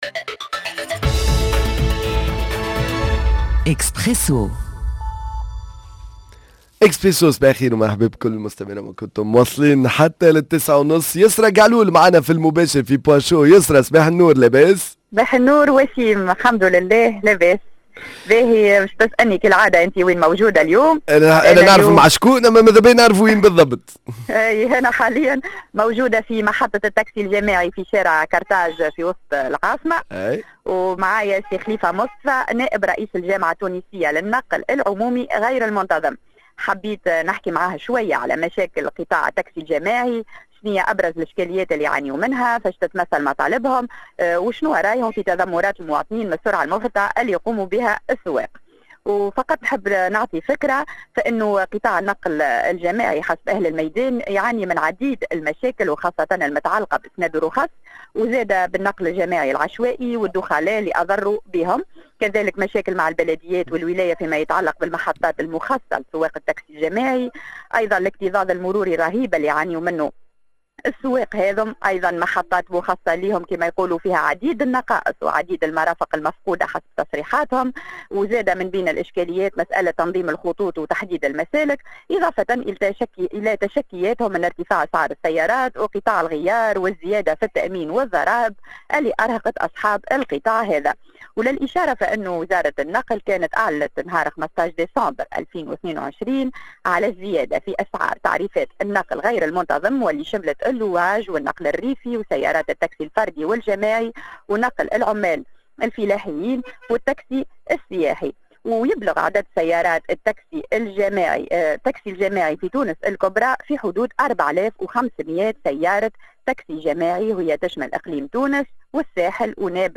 التاكسي الجماعي هل هو الحل لأزمة النقل مباشرة